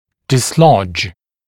[dɪs’lɔʤ][дис’лодж]перемещать, смещать; удалять, вытеснять; сбивать с места